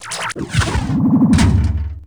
thunk.wav